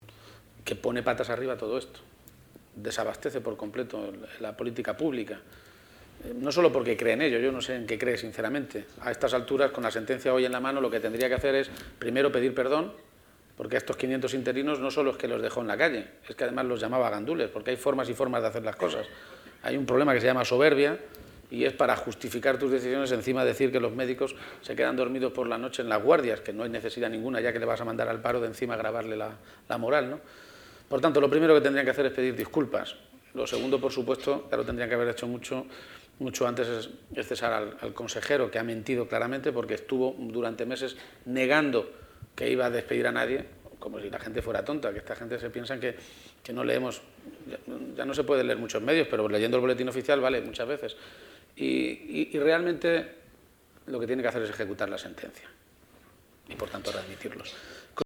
El secretario general del PSOE de Castilla-La Mancha, que ha participado en las Jornadas del Partido Socialista de Euskadi “Reflexiones sobre el futuro”, señala que el PSOE "ha decidido tomar riesgos con las primarias para seguir siendo el partido del cambio”
Intervención Page en jornadas PSOE Euskadi